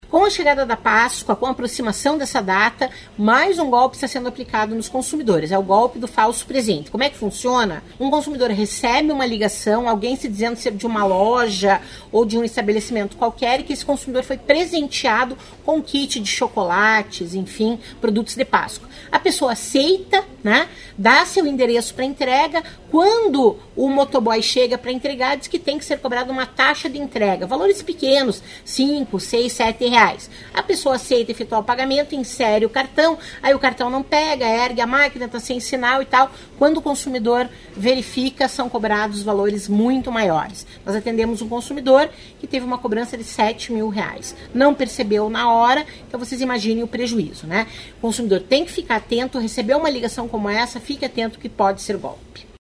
Sonora da diretora do Procon-PR, Claudia Silvano, sobre novo golpe do falso presente de Páscoa